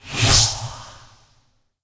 UI_EtherealWindow_Close.wav